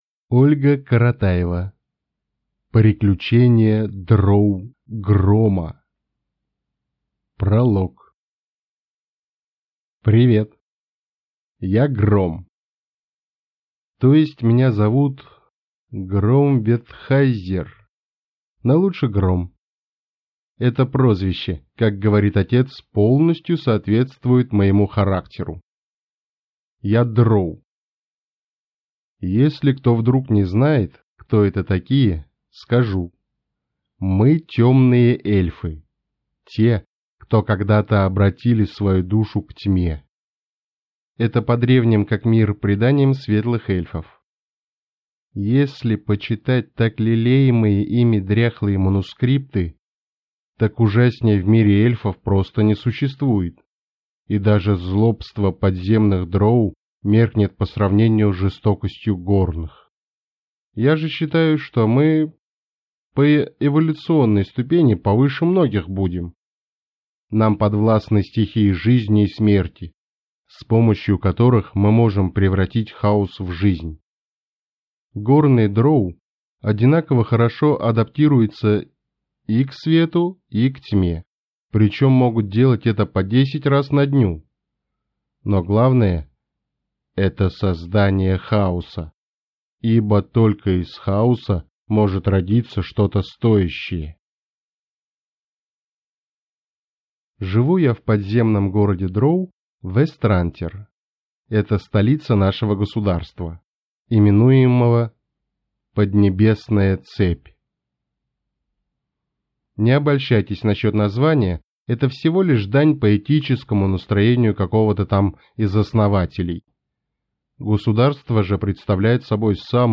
Аудиокнига Приключения дроу Грома | Библиотека аудиокниг